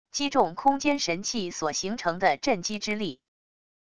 击中空间神器所形成的震击之力wav下载